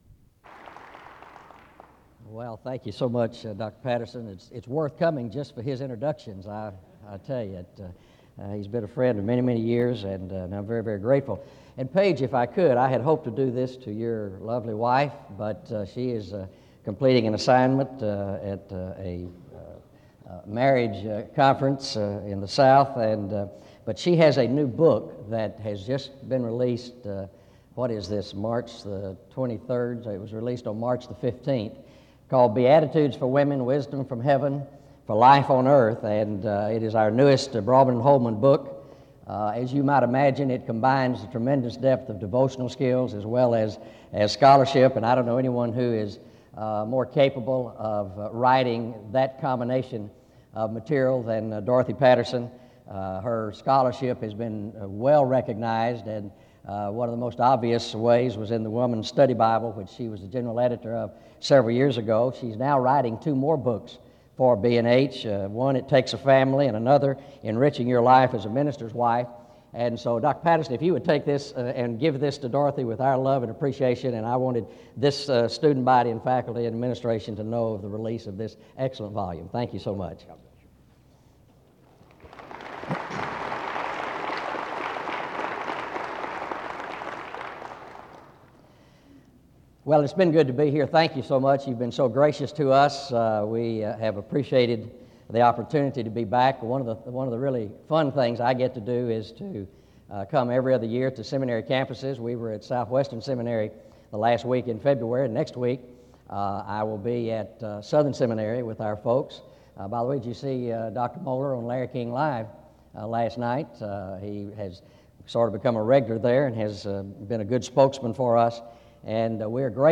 Home SEBTS Chapel